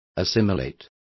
Also find out how digerir is pronounced correctly.